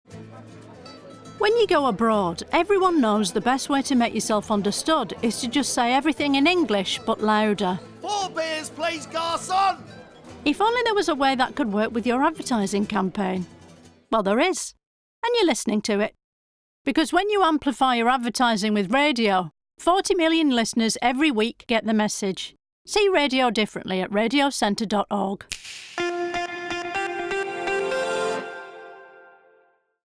August’s Ad of the Month hits a familiar note — it’s our own campaign.
Front and centre is the unmistakable voice of Diane Morgan, guiding listeners from the blissfully oblivious Brits abroad, to the roaring electricity of a football crowd, and even taking time to salute the unsung glory of the human ear.